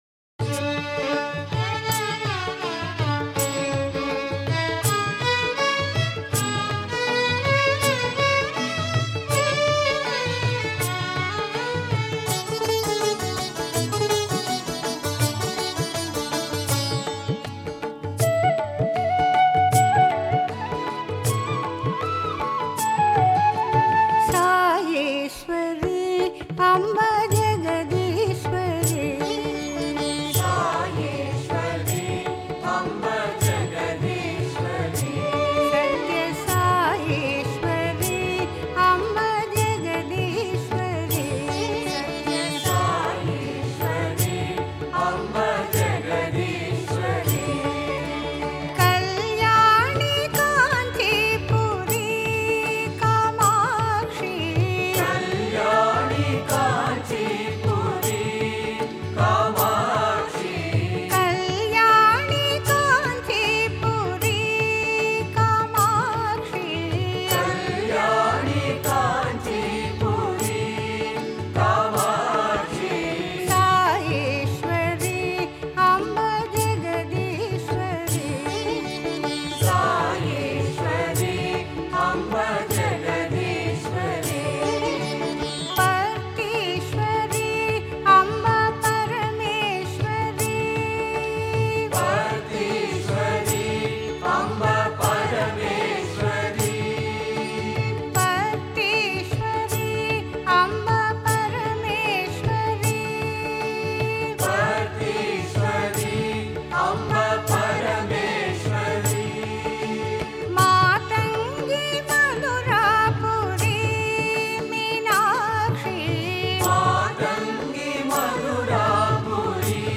Author adminPosted on Categories Devi Bhajans